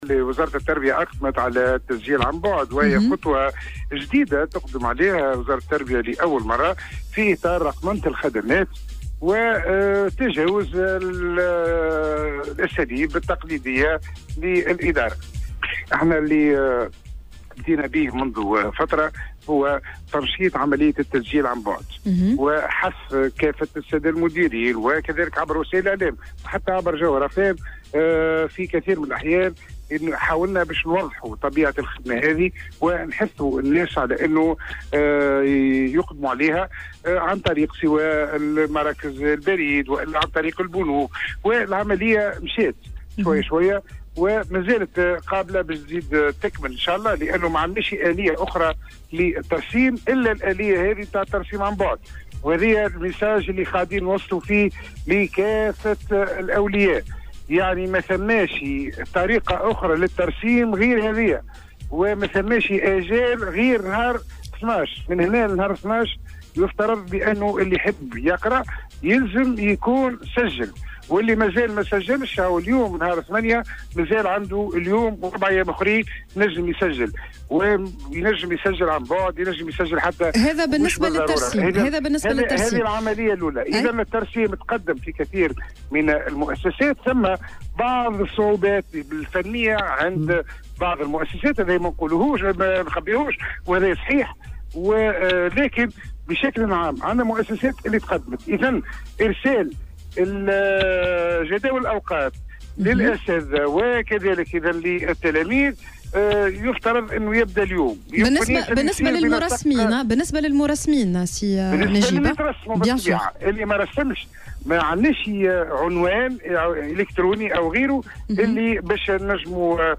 المندوب الجهوي للتربية بسوسة